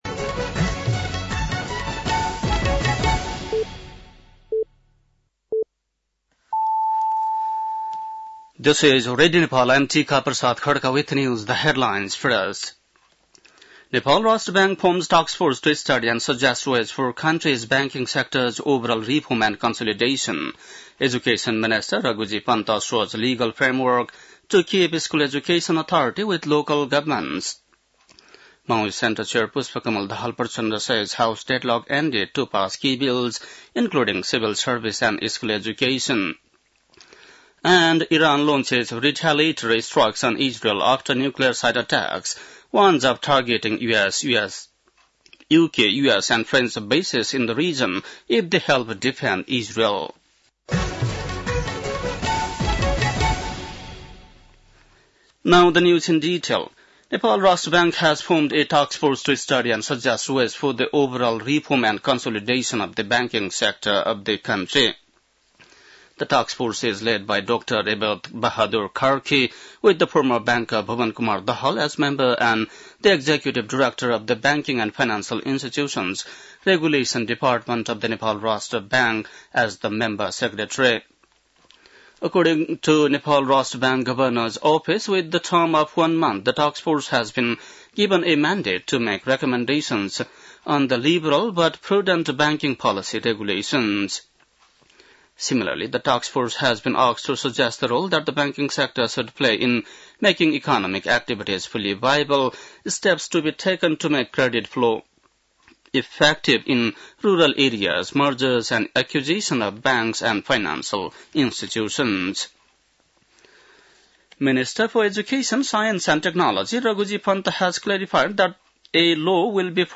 बेलुकी ८ बजेको अङ्ग्रेजी समाचार : ३१ जेठ , २०८२
8.-pm-english-news-1-1.mp3